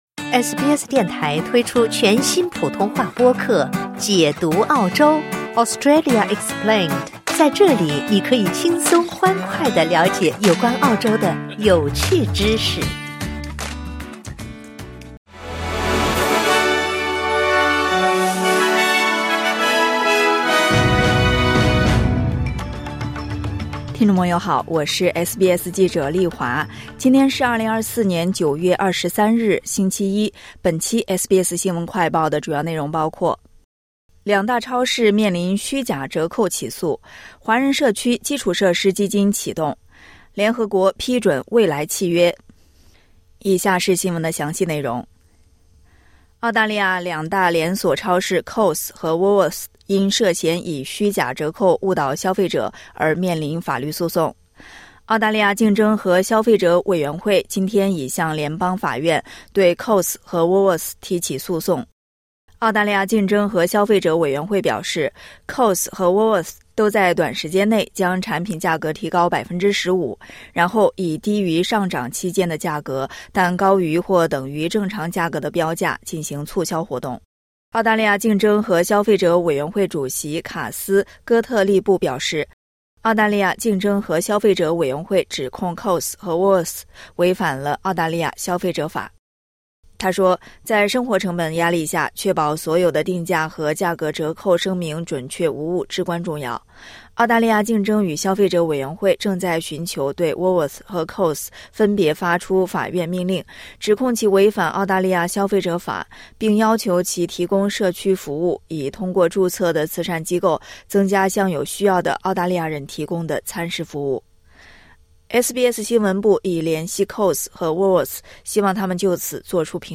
【SBS新闻快报】Coles和Woolworths面临虚假折扣起诉